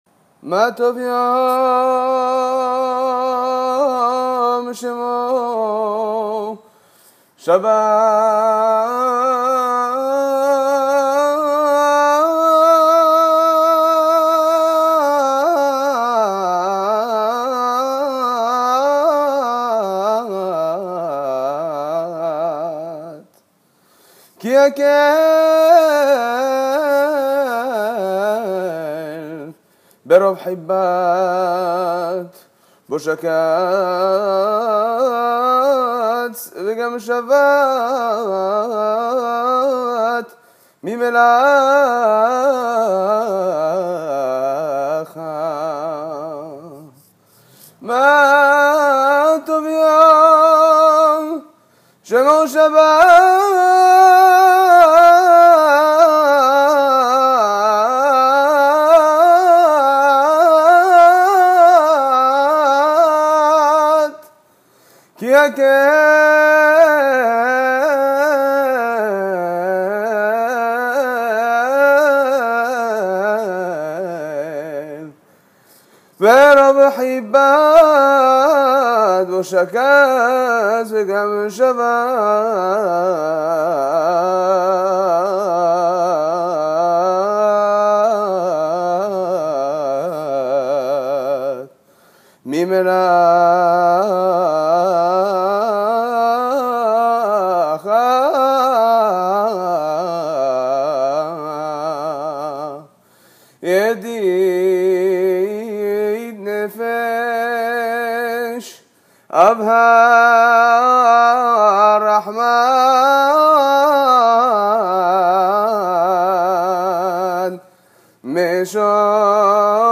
Makam Ram El Maya) - Nigoun du Maroc
Hazanout